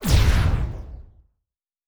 Sci Fi Explosion 09.wav